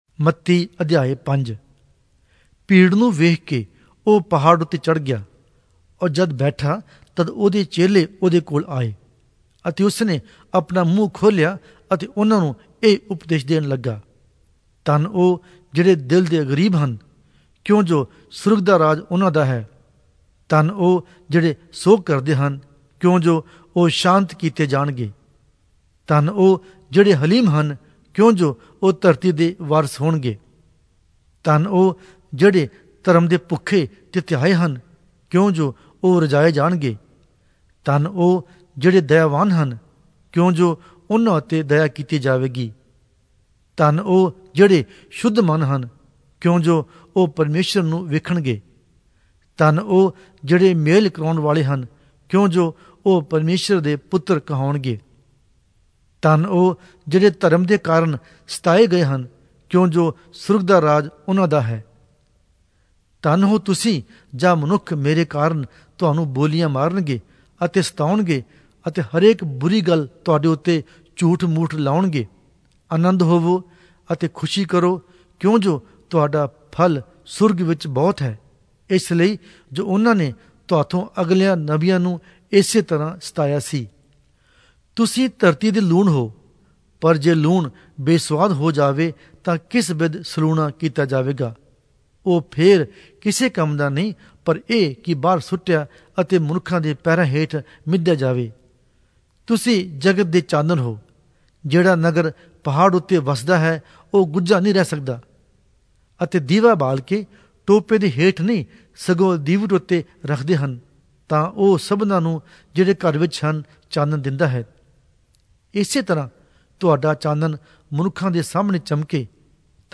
Punjabi Audio Bible - Matthew 28 in Irvmr bible version